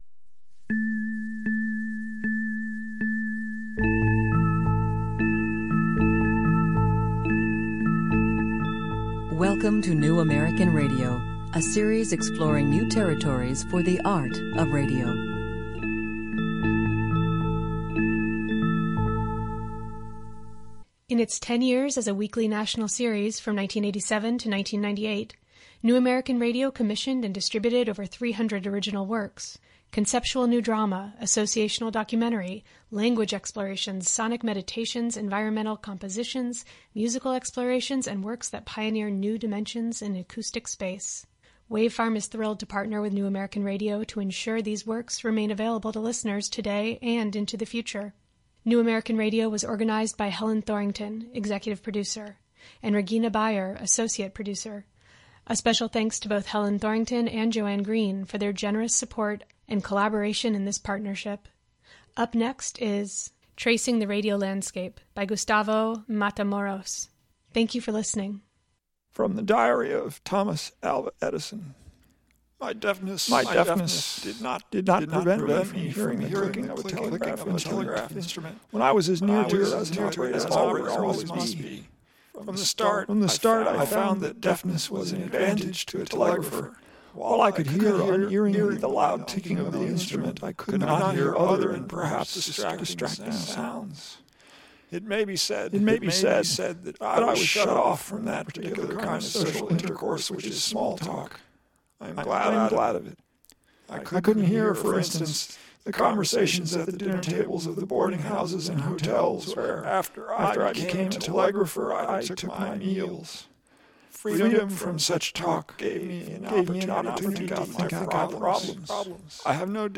A sound portrait based on "snapshots" of living room environments that include a radio as an active sound element. The first layer of the work is made up of these recordings. The second layer is constructed from a selection of the same sounds, but manipulated and processed by computer.